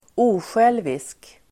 Ladda ner uttalet
Folkets service: osjälviskt osjälvisk adjektiv, unselfish Uttal: [²'o:sjel:visk] Böjningar: osjälviskt, osjälviska Definition: inte självisk, oegennyttig osjälviskt , altruistic , unselfish , altruistically